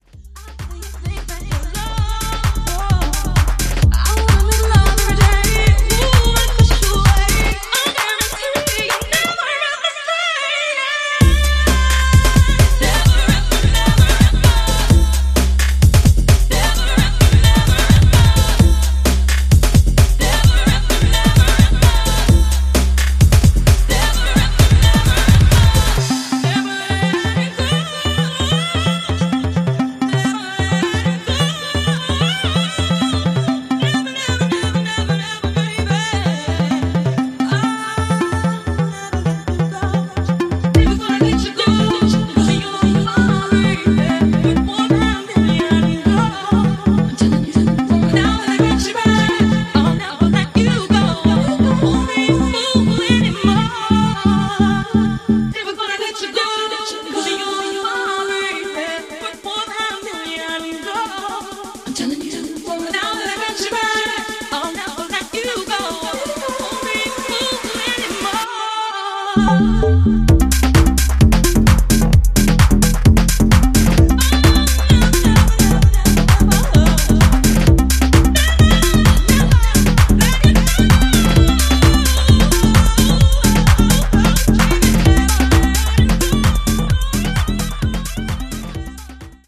both driven by irresistible samples and undeniable grooves.
upfront and built for the dancefloor